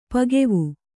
♪ pagevu